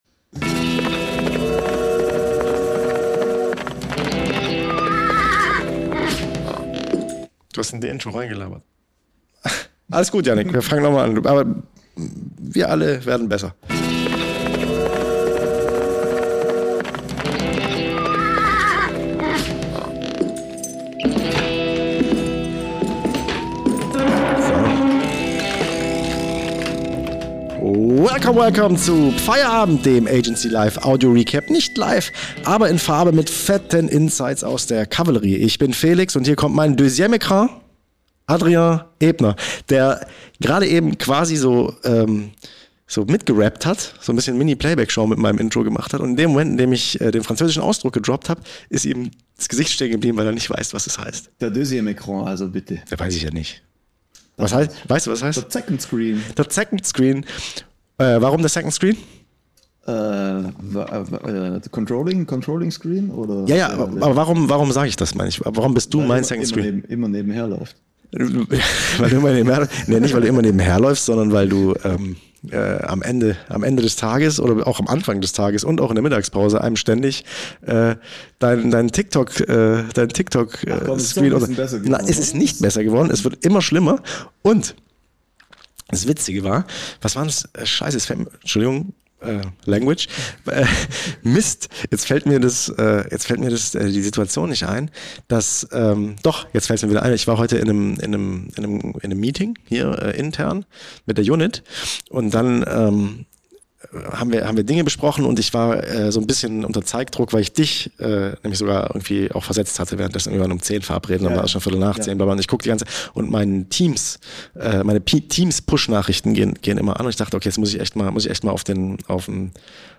Beschreibung vor 1 Jahr REKORD: Die längste Sommerpause der Welt hat ein Ende – aber mit Audio-Stolper-Start – also doch alles wie immer. Ab sofort direkt aus Omas Kaffeestube … äh natürlich dem neuen Studio.